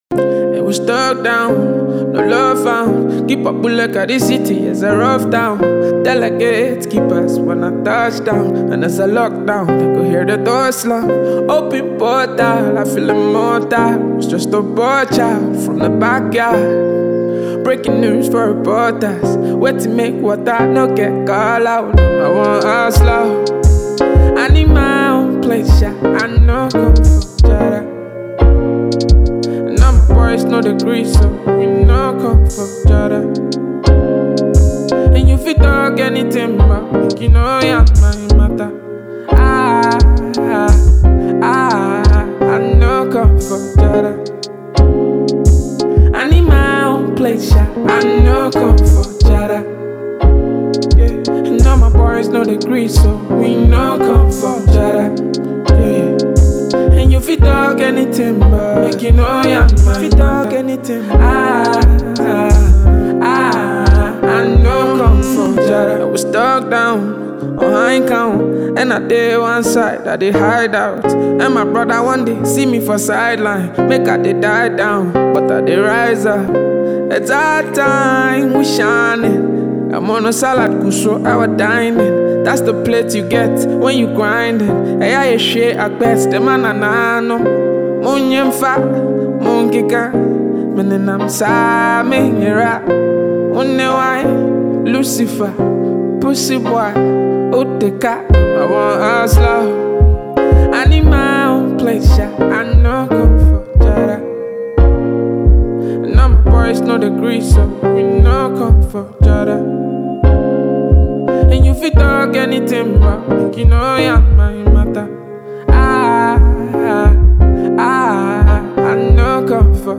Enjoy this amazing production from this young rapper.